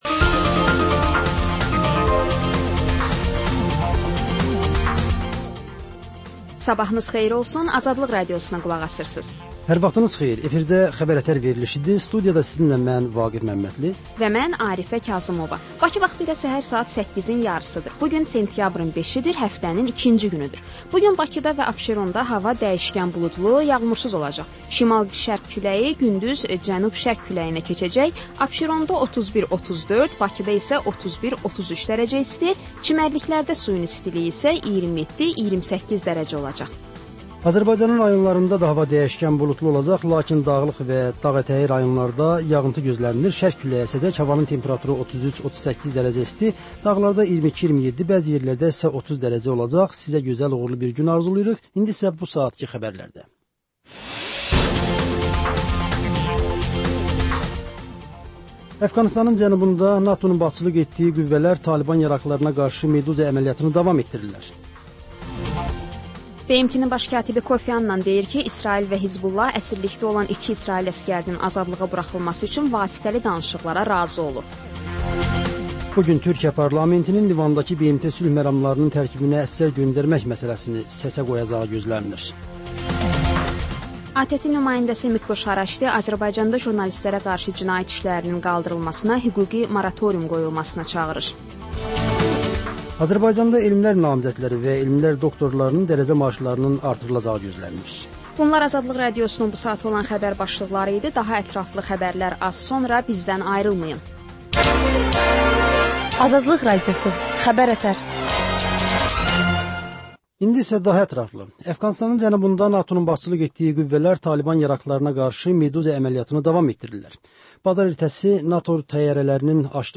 Səhər-səhər, Xəbər-ətərI Xəbər, reportaj, müsahibə